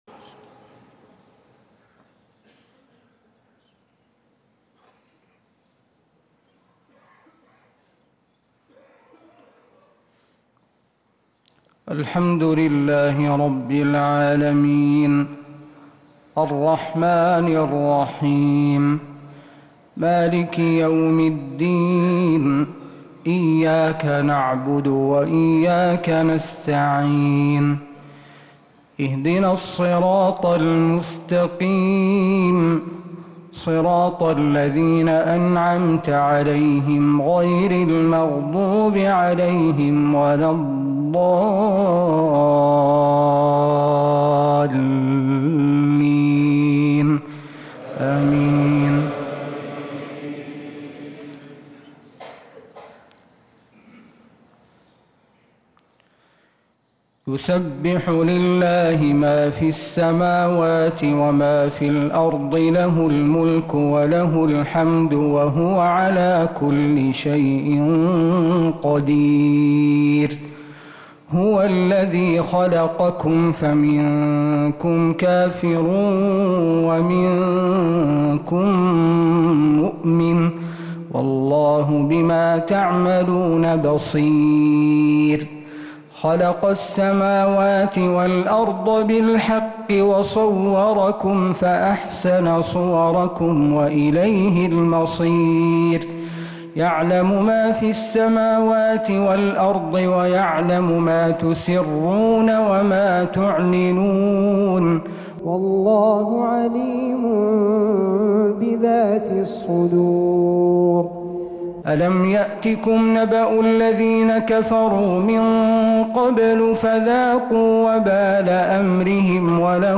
صلاة الفجر